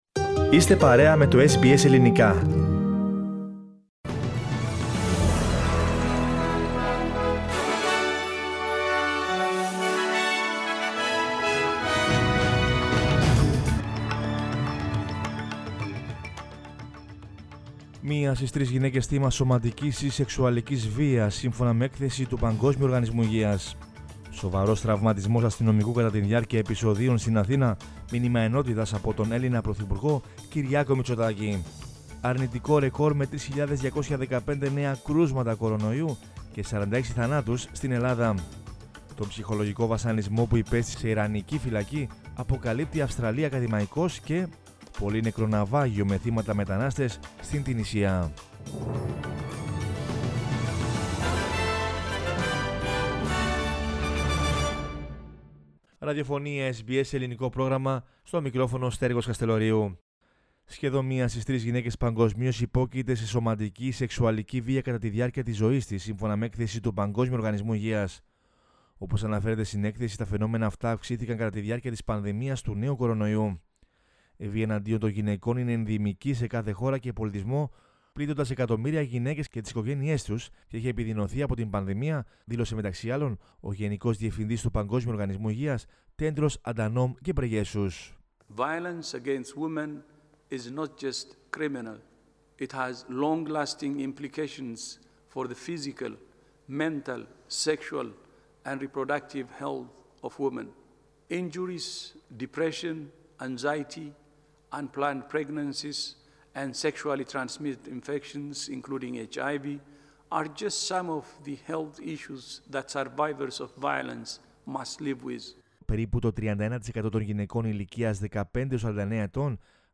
News in Greek from Australia, Greece, Cyprus and the world is the news bulletin of Wednesday 10 March 2021.